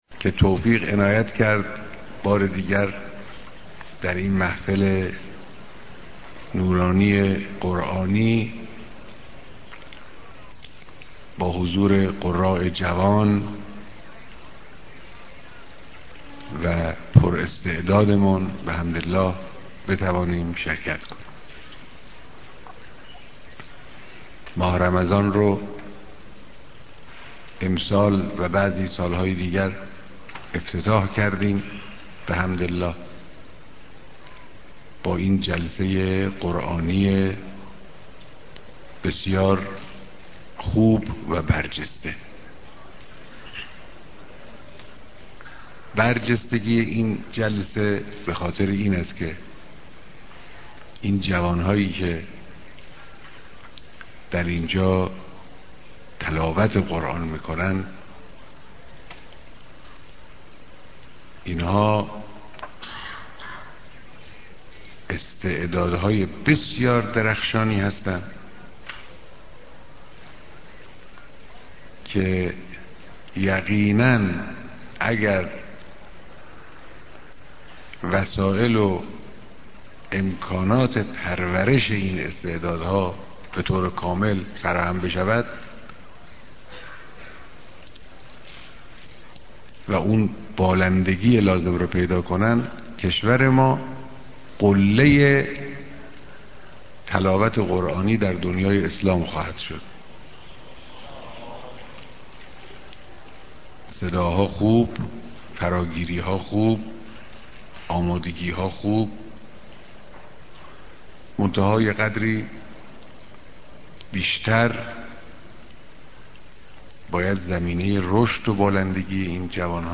محفل ضيافت نور و انس با قرآن با حضور قاريان ممتاز، حافظان قرآن و گروه هاى جمعخوانى
بيانات ديدار قاريان قرآن كريم در ماه مبارك رمضان